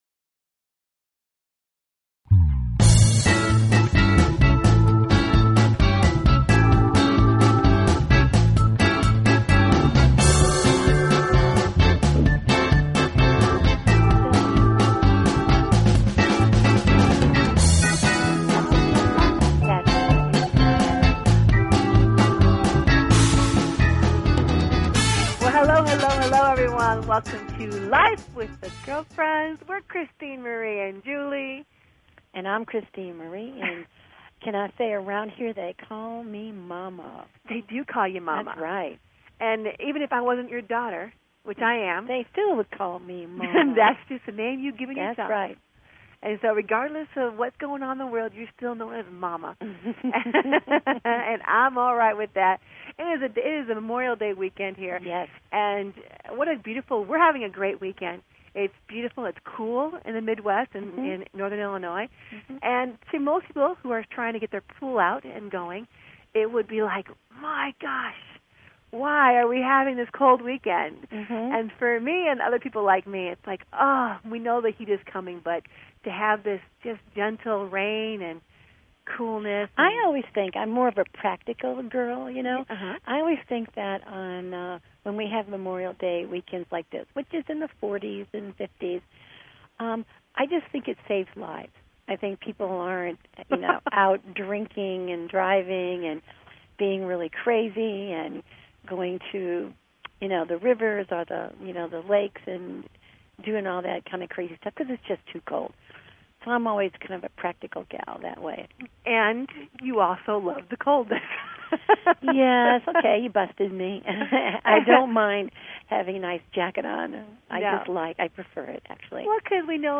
Talk Show Episode, Audio Podcast, Life_With_The_Girlfriends and Courtesy of BBS Radio on , show guests , about , categorized as